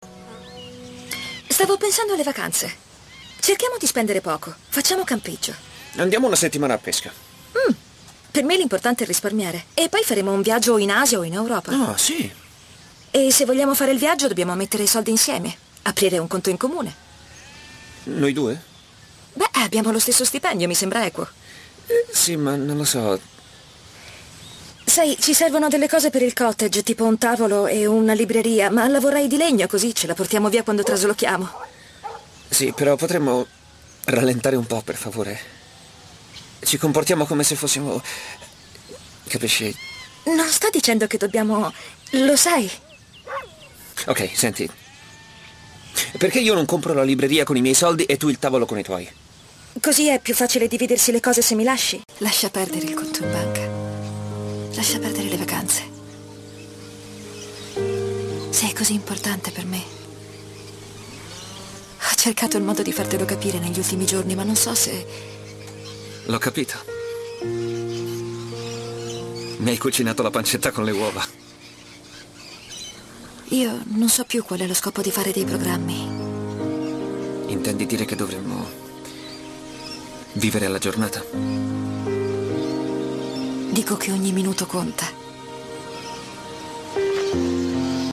nel telefilm "Le sorelle McLeod", in cui doppia Gillian Alexy.